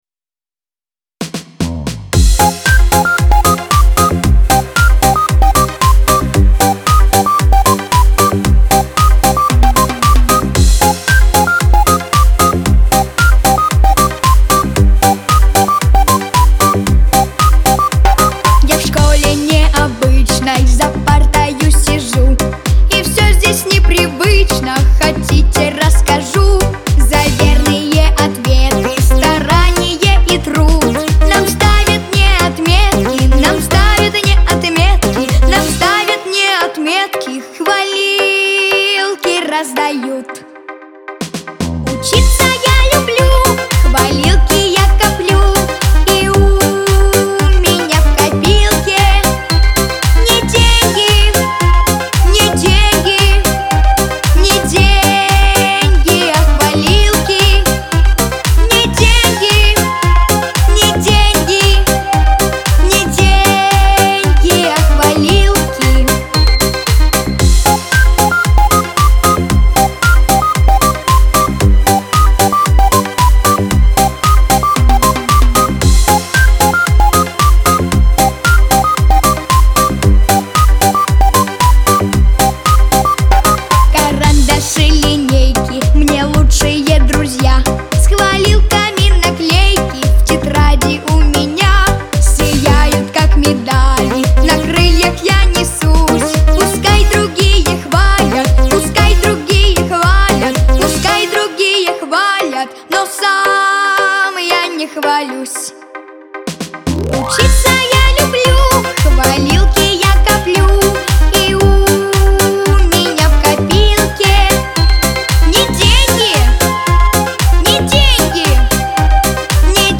ансамбль
Веселая музыка